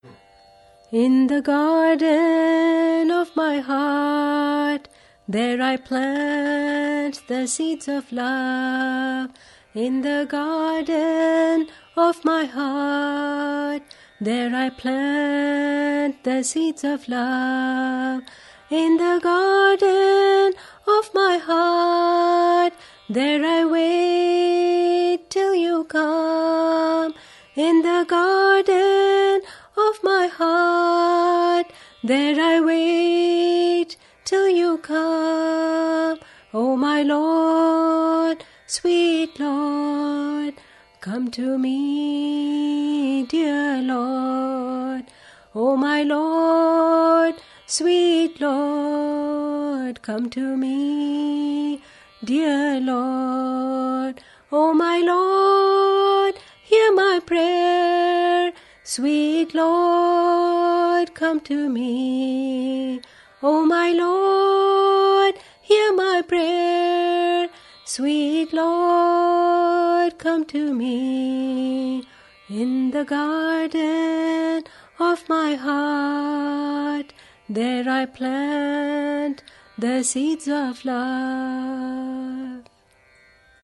1. Devotional Songs
Major (Shankarabharanam / Bilawal)
8 Beat / Keherwa / Adi
5 Pancham / G
2 Pancham / D